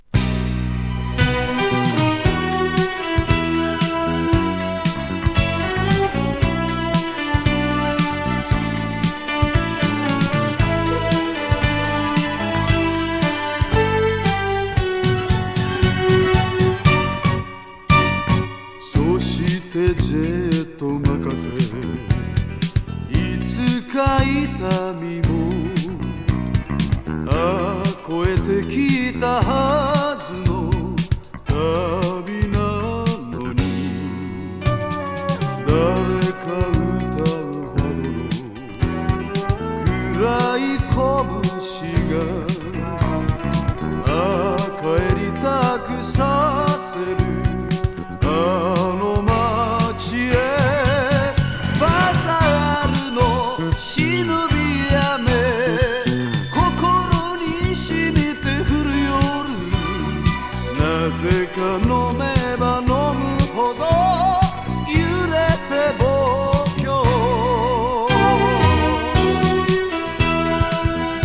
Japanese 和ソウル / ディスコ / ファンク レコード